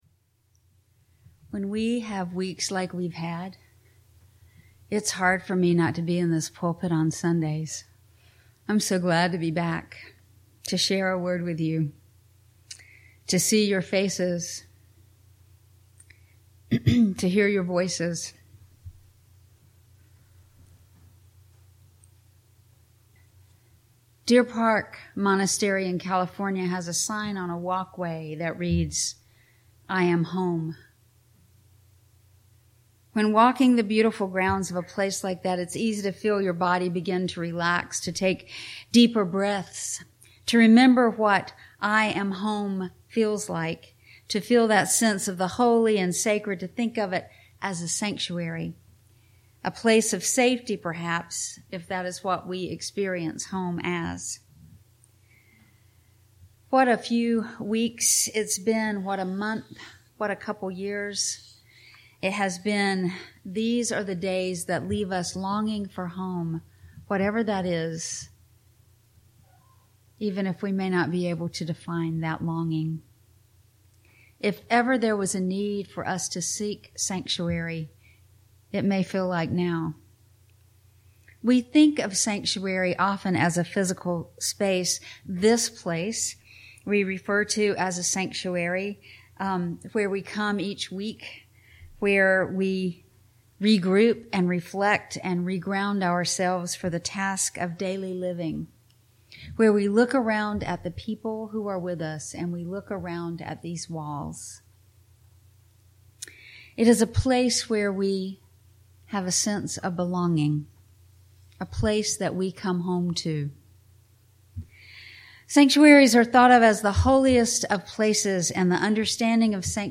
This sermon explores the deep human necessity for sanctuary and home, framing them not merely as physical locations but as internal states of peace and belonging. The speaker suggests that in times of societal upheaval and personal exhaustion, we must cultivate “islands of sanity” through intentional breathing, connection with nature, and communal support.